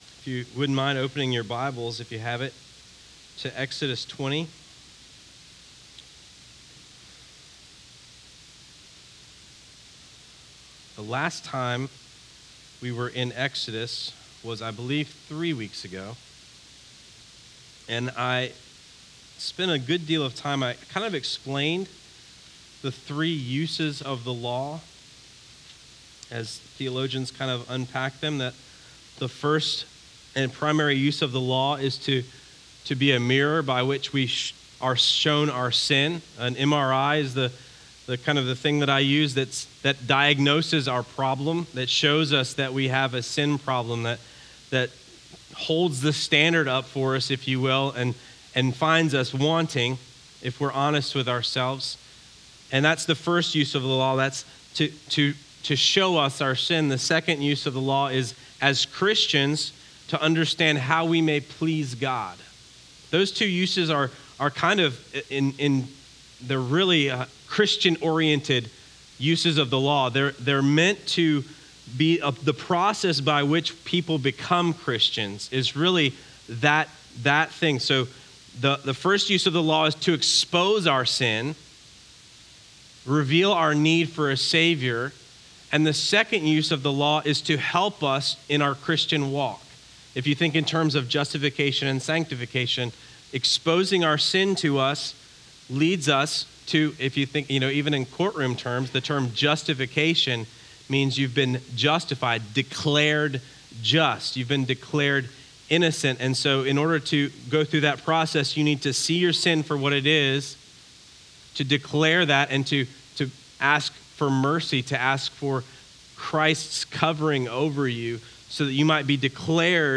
There is also a great testimony during the sermon from someone who has been through the Kairos Prison Ministry program, and recently released.